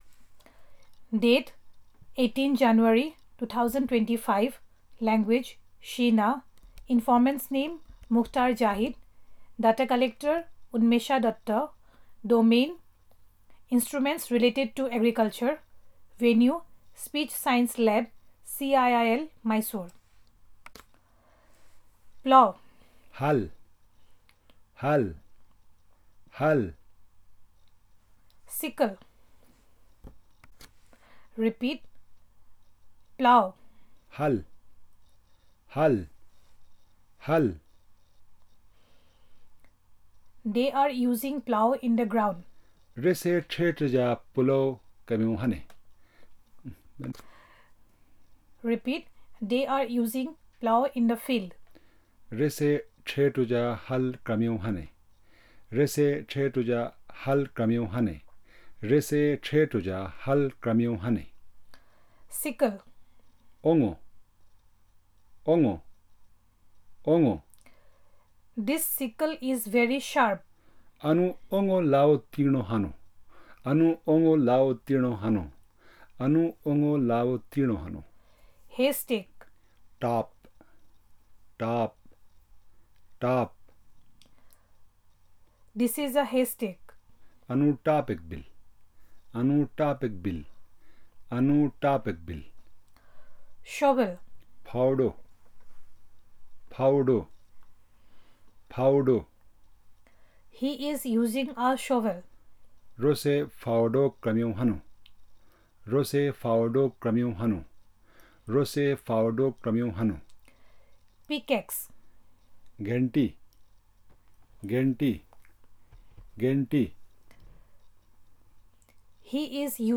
NotesThis is an elicitation about different agricultural instruments. The equivalents of all the instrument's names given in Pictorial Glossary along with their usage in simple sentences has been recorded properly.